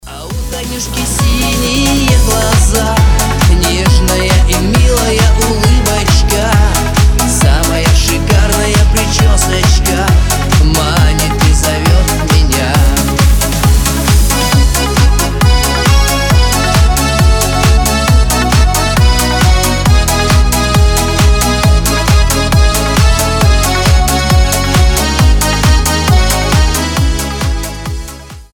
• Качество: 320, Stereo
позитивные
аккордеон